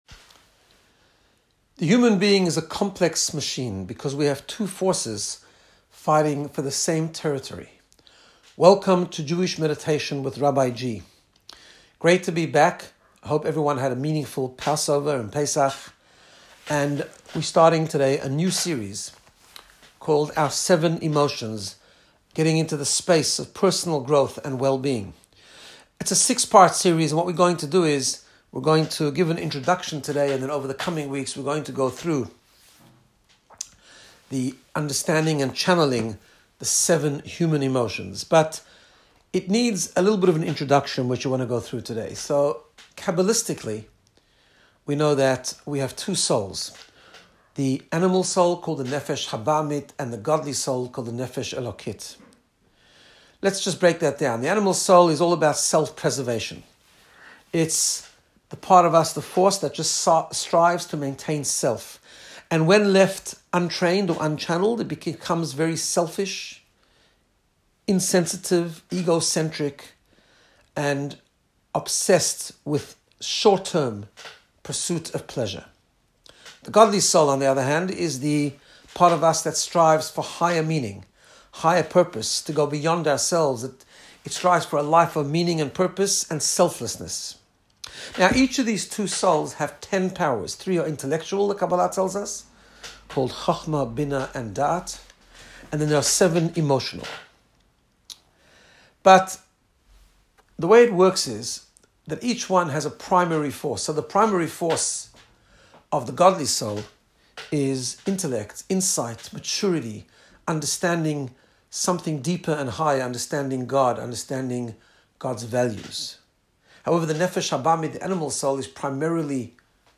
Guided Meditation to get you in the sapce of Personal Growth and Wellbeing. In this episode learn about the 2 souls and forces within the human experience and what it means to chanel negative and toxic emotions, harnessing the good.
Meditation-achrei-kedoshim.m4a